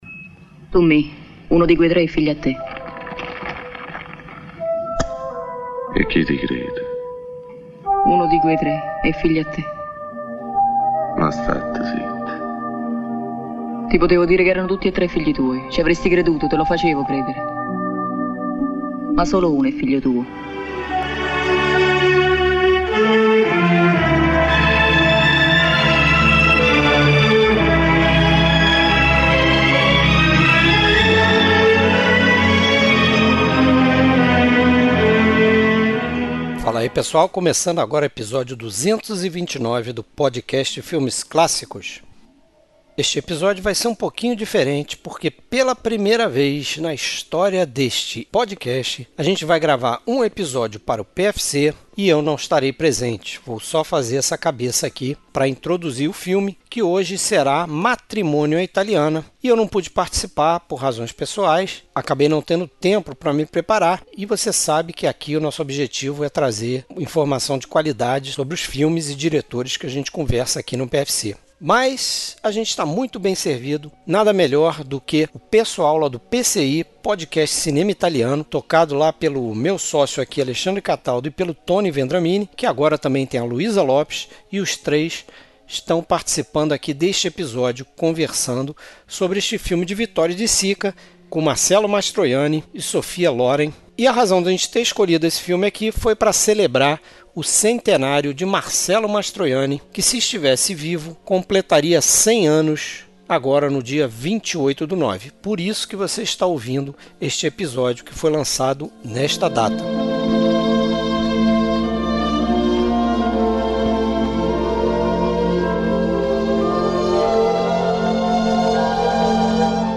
Trilha Sonora: trilha sonora que acompanha o filme.